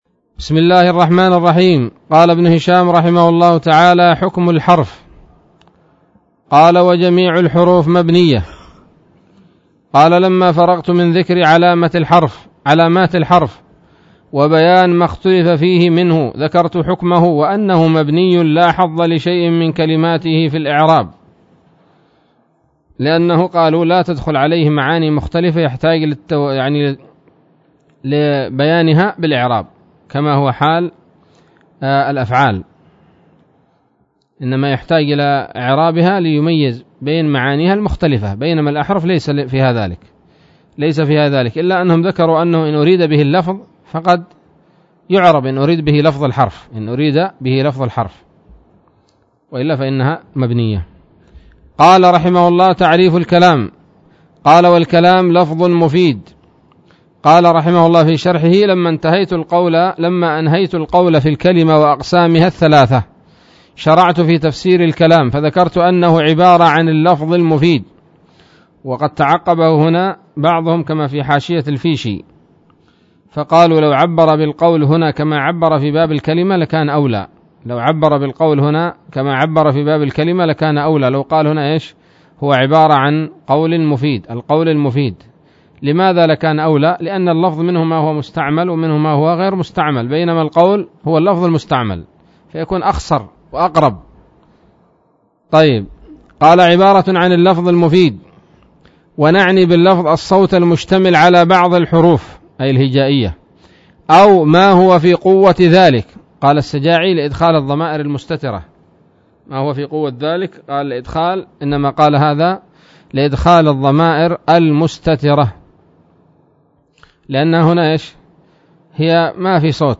الدرس السادس عشر من شرح قطر الندى وبل الصدى